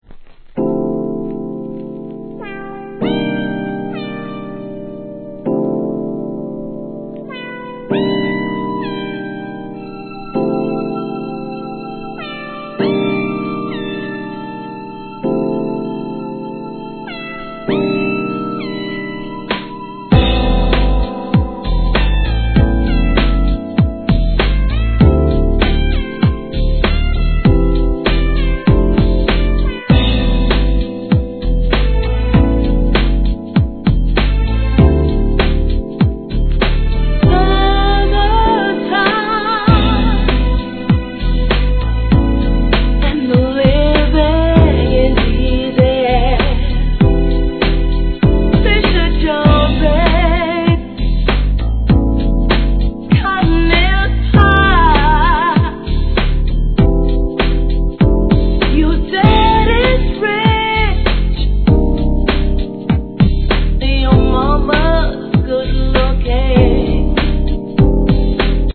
HIP HOP/R&B
CLASSIC GARAGE MIX